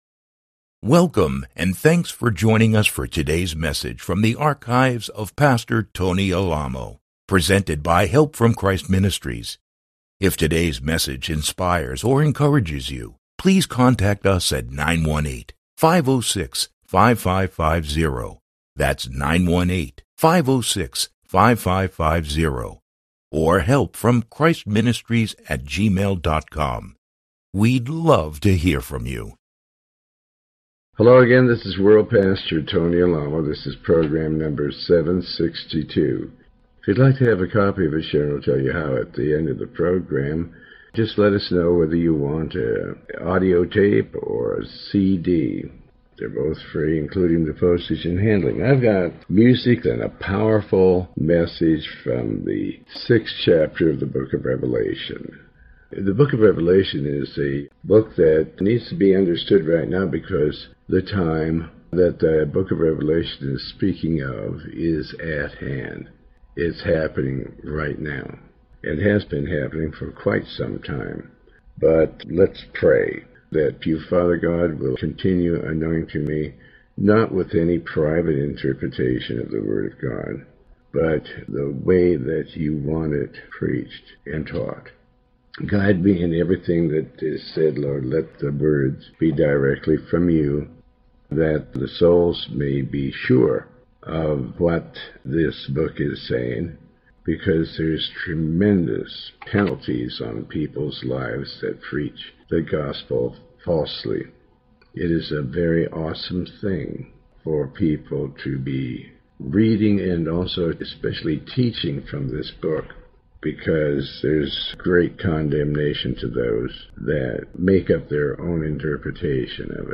Pastor Alamo reads and comments on the Book of Revelation chapter 6. This program is part of a series covering the entire Book of Revelation